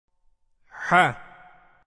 1. Tabii Med (Medd-i Tabii):
Tabii med hareke uzunluğunun iki katı uzatılır.